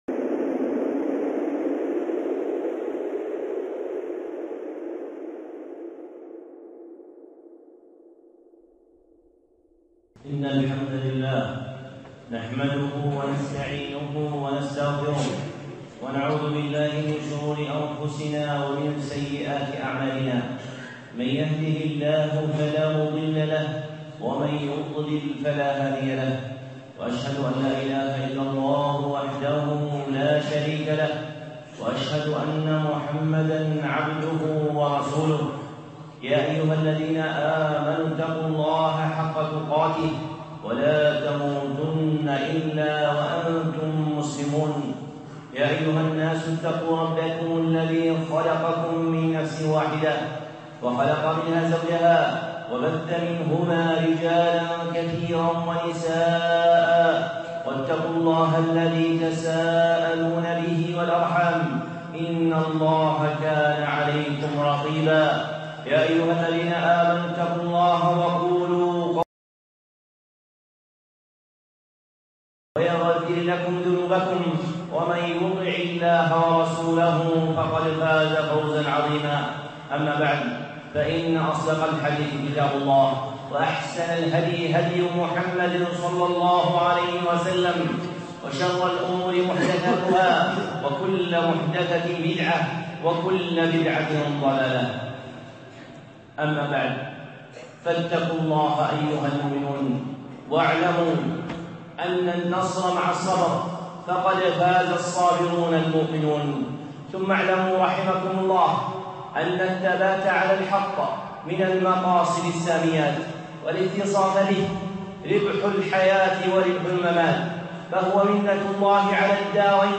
خطبة (لا تنسوا جيشنا الباسل) الشيخ صالح العصيمي